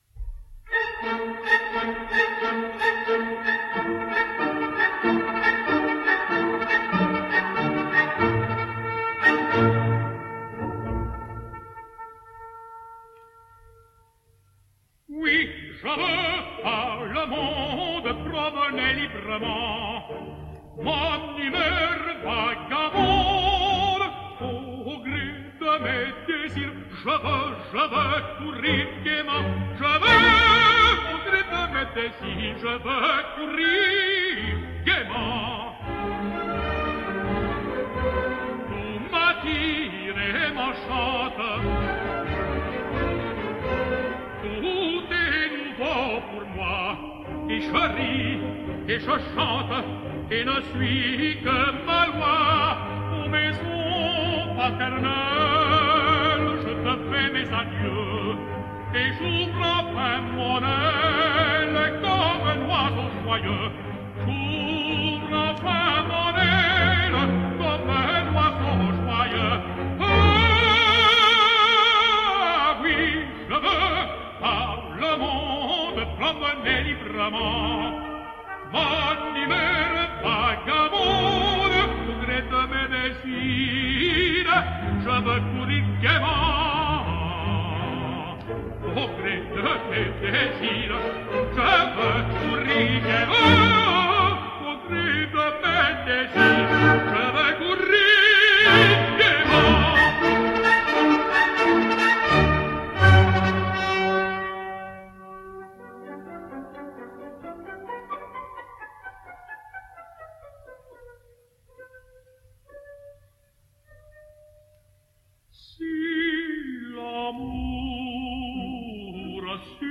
French Tenor
Qui Je Par Le Monde / Mignon / 1977 – Alain Vanzo